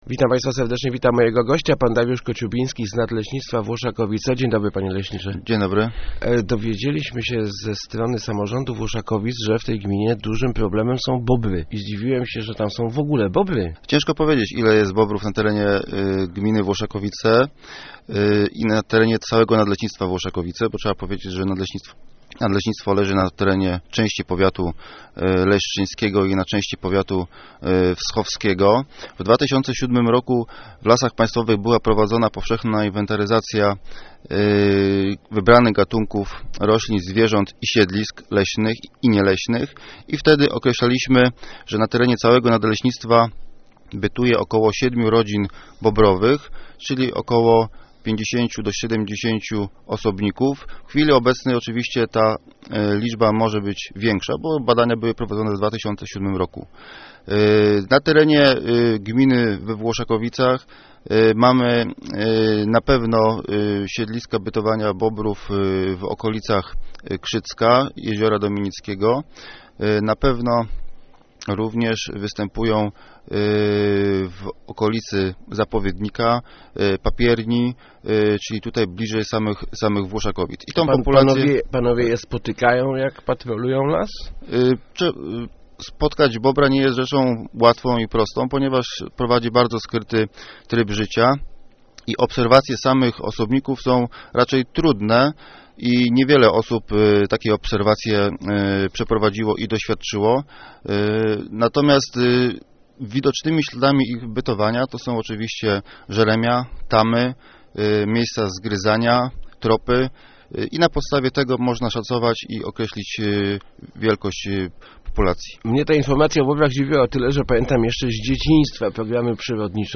Start arrow Rozmowy Elki arrow Bobry: plaga pod ochroną